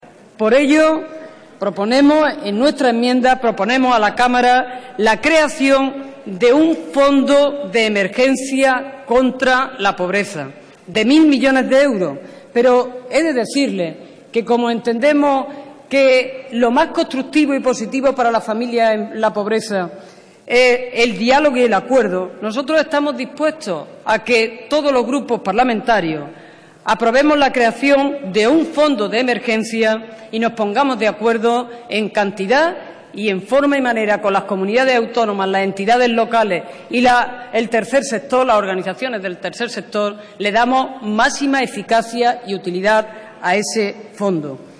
Rosa Díez. Pleno del Congreso 9/04/2013. Solicitud de creación de un fondo de 1000 millones de euros contra la pobreza